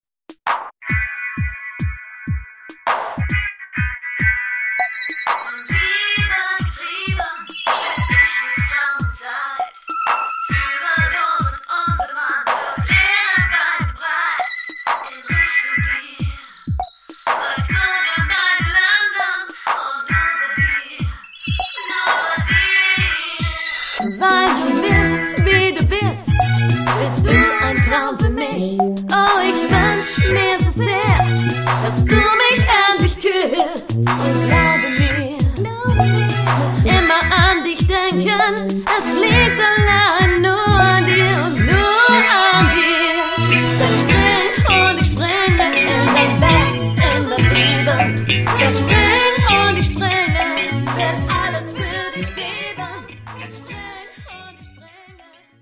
bass
drums/percussion